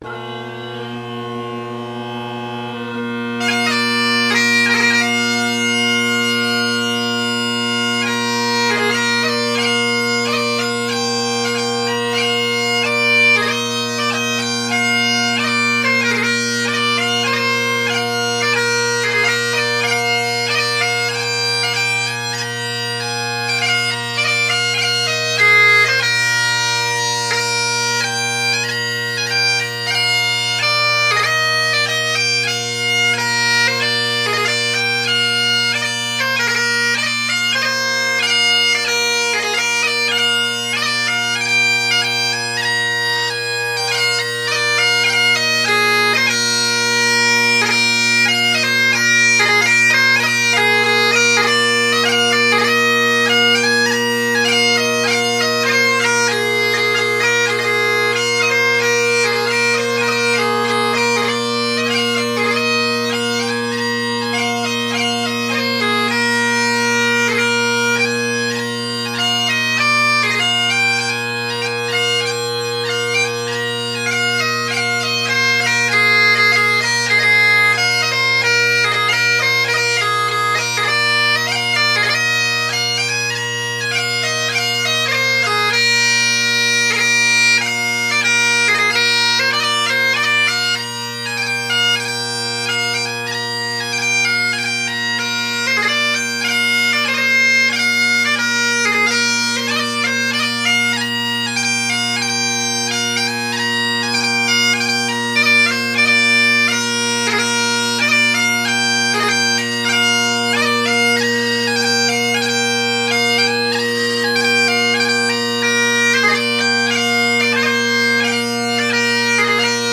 Great Highland Bagpipe Solo
Also, no one else was home so I got to play in a bigger room.